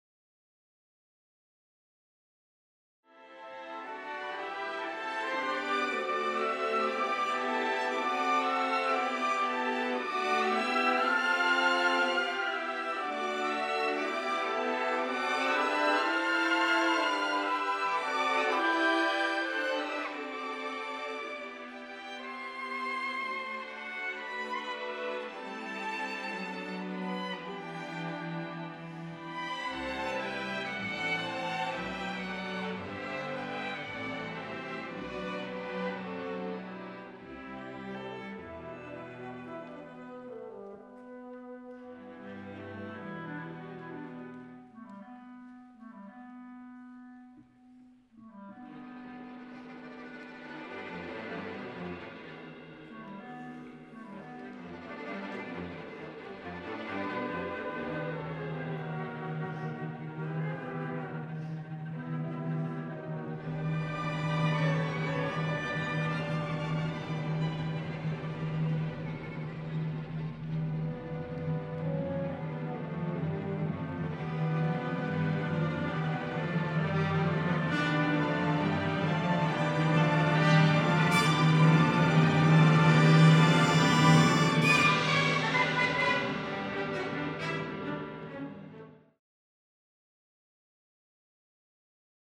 Southeast Missouri Symphony Performance Excerpts